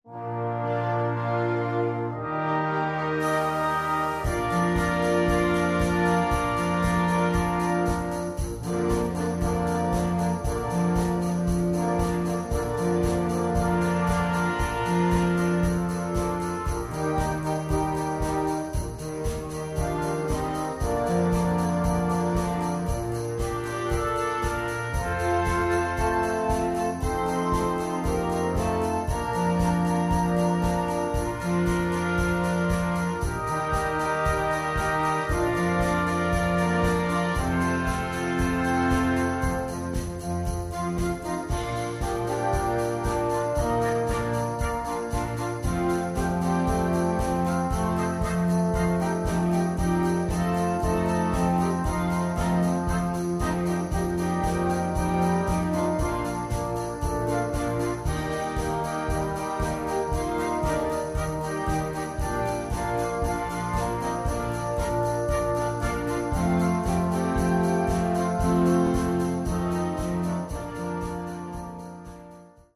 Deutsch-Pop
Tonart Bb-Dur